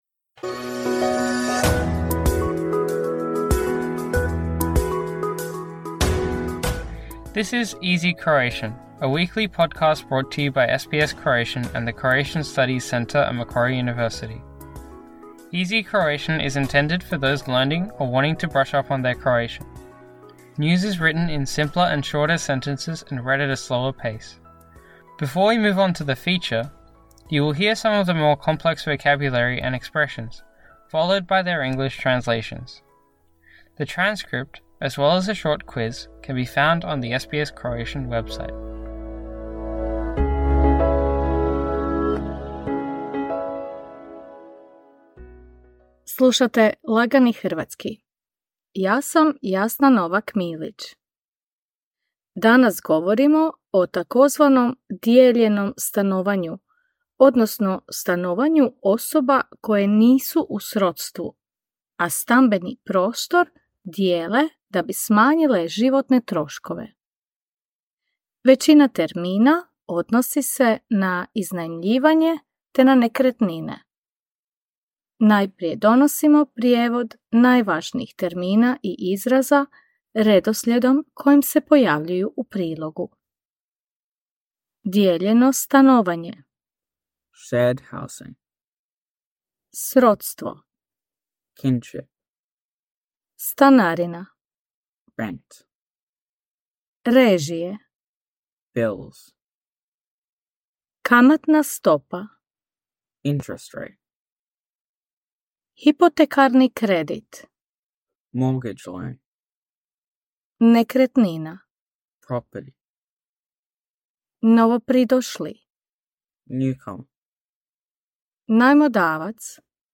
“Easy Croatian” is intended for those learning or wanting to brush up on their Croatian. News is written in simpler and shorter sentences and read at a slower pace. Before we move on to the feature, you will hear some of the more complex vocabulary and expressions, followed by their English translations.